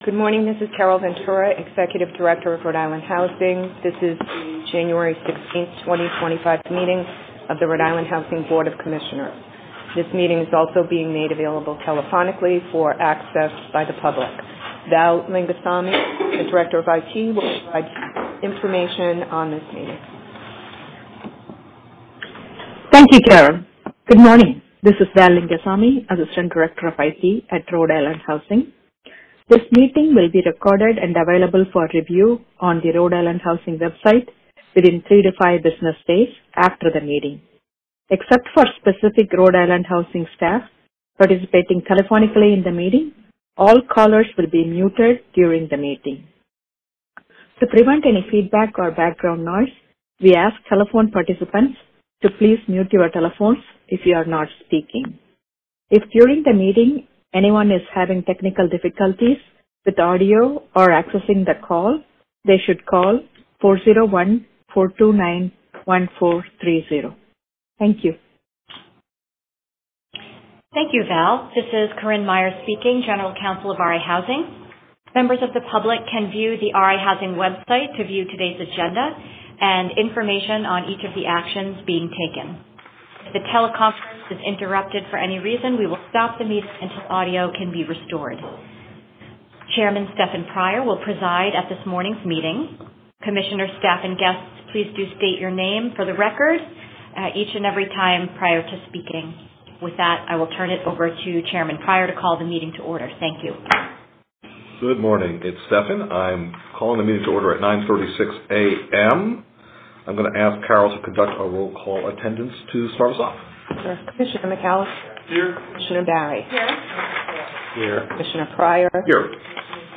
Recording of RIHousing Board of Commissioners Meeting: 1.16.2025